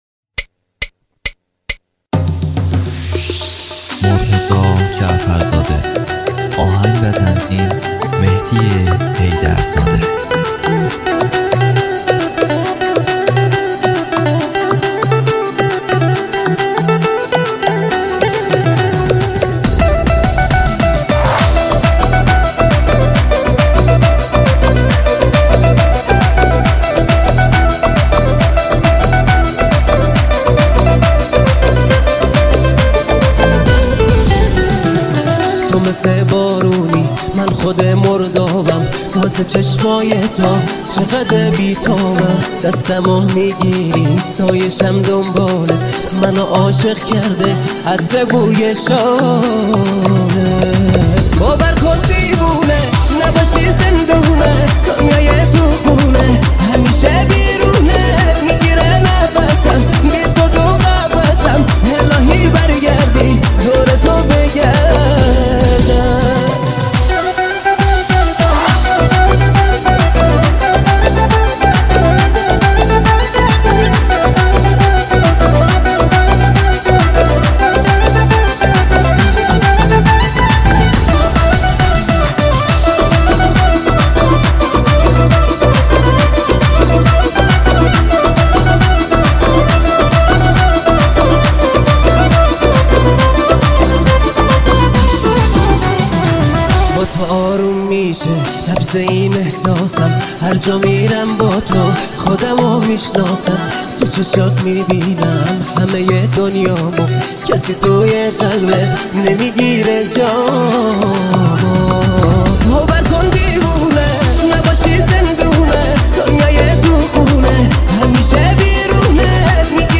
• آهنگ جدید ~ ریمیکس